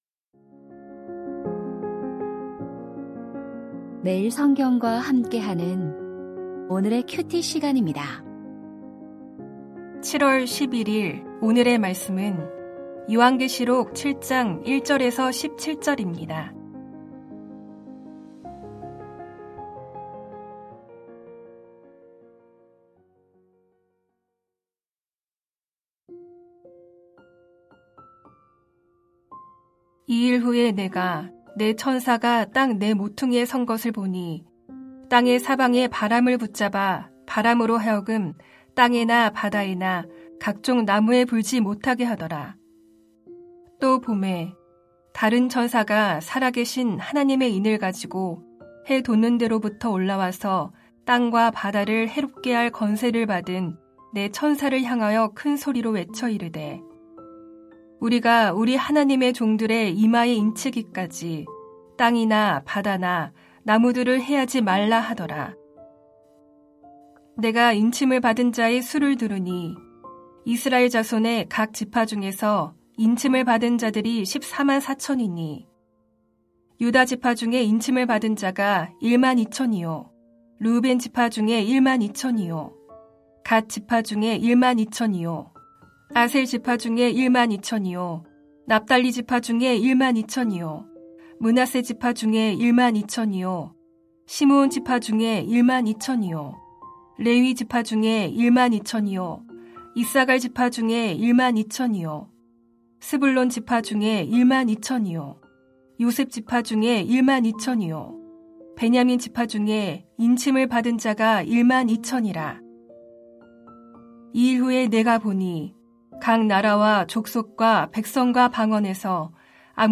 요한계시록 7:1-17 위로와 소망의 메시지 2025-07-11 (금) > 오디오 새벽설교 말씀 (QT 말씀묵상) | 뉴비전교회